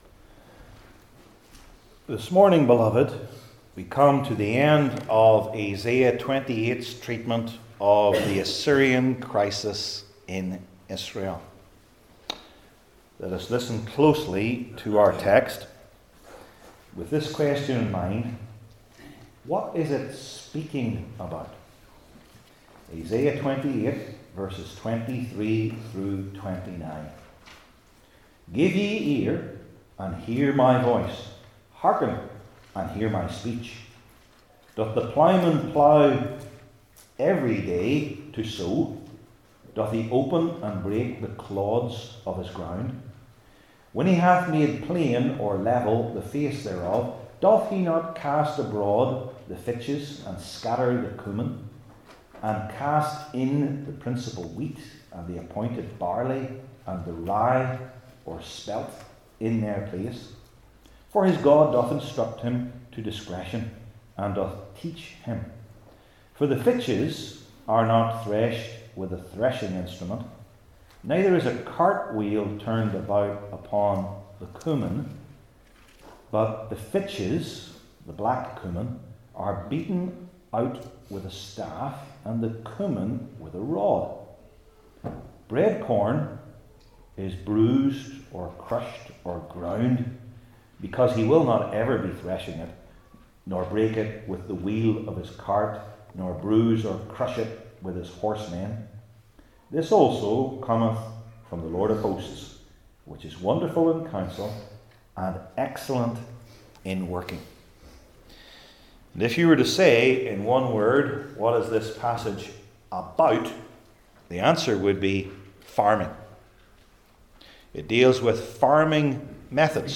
Isaiah 28:23-29 Service Type: Old Testament Sermon Series I. Agriculture II.